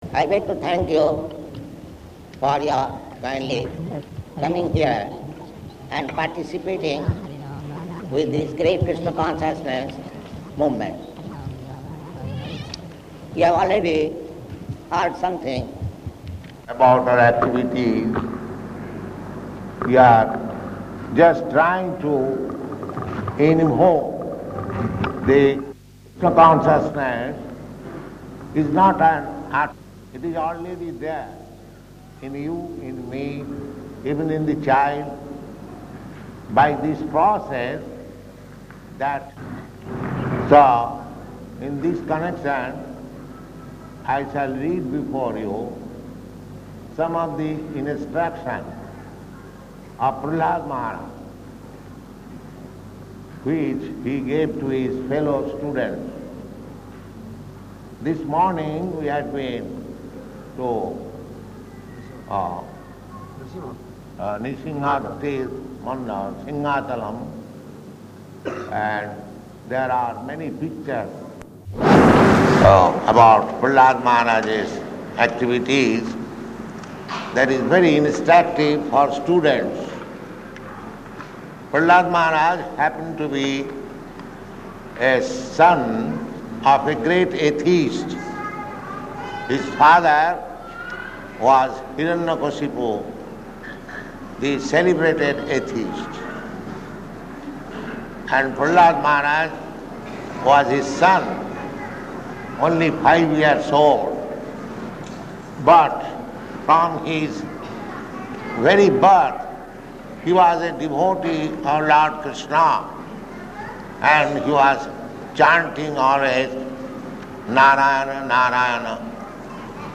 Type: Lectures and Addresses
Location: Visakhapatnam
[bad audio for 00:18]